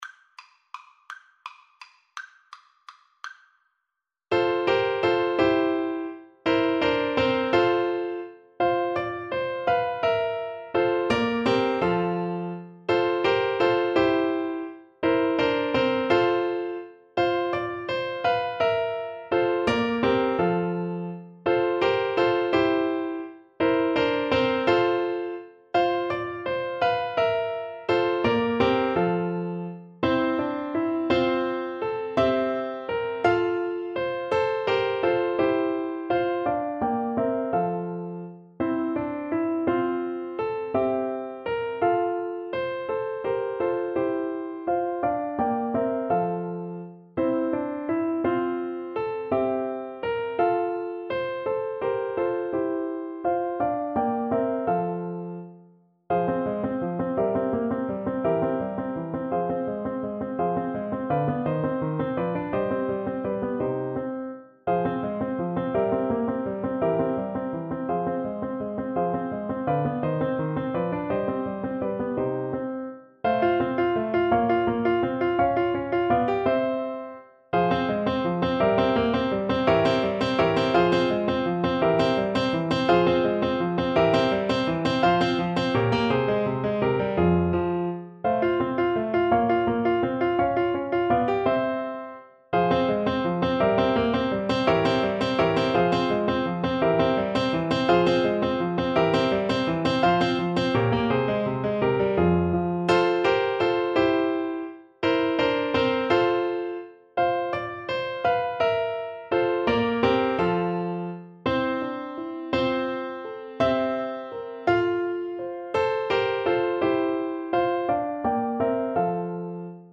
Lustig (Happy) .=56
3/8 (View more 3/8 Music)
Classical (View more Classical Clarinet Music)